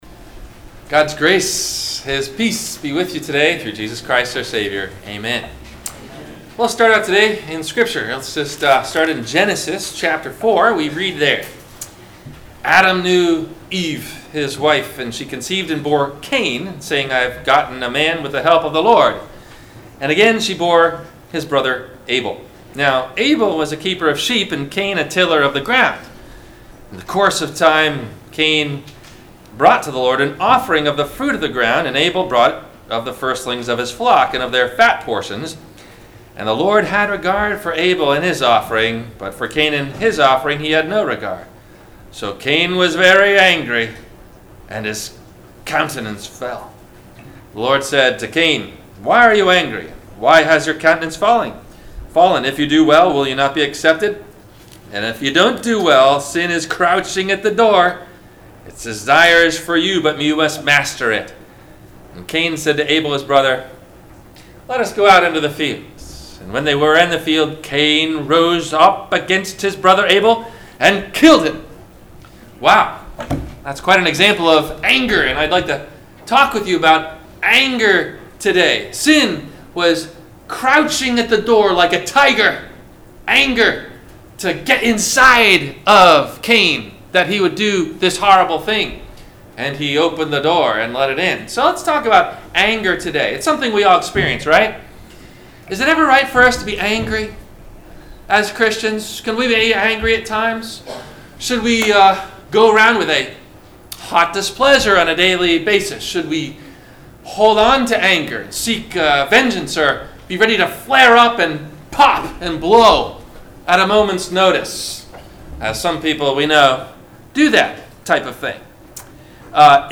Have a Comment or Question about the Sermon?
WMIE Radio – Christ Lutheran Church, Cape Canaveral on Mondays from 12:30 – 1:00